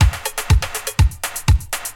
• 122 Bpm 80s Breakbeat B Key.wav
Free drum loop - kick tuned to the B note. Loudest frequency: 1849Hz
122-bpm-80s-breakbeat-b-key-qoC.wav